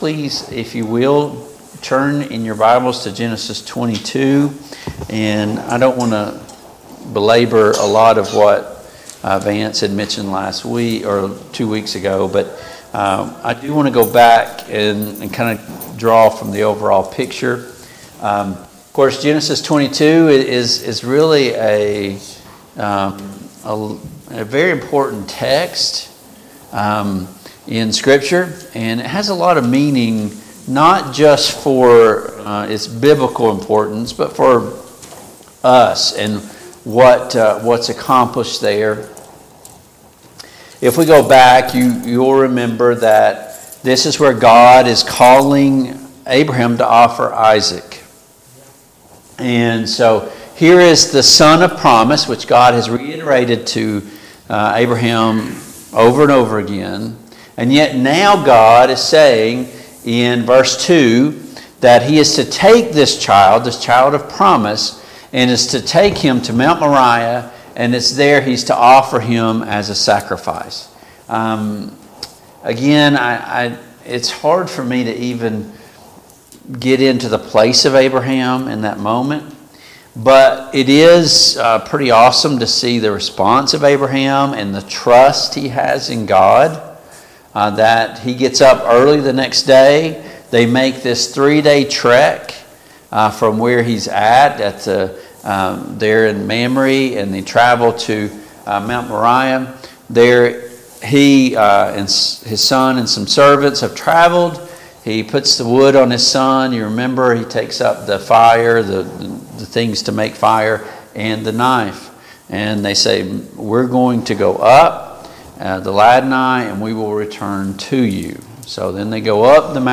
Genesis 23 Service Type: Family Bible Hour Topics: Abraham and Isaac , Abraham and Sarah « Is it time for a reset?